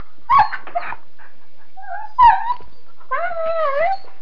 دانلود صدای حیوانات جنگلی 128 از ساعد نیوز با لینک مستقیم و کیفیت بالا
جلوه های صوتی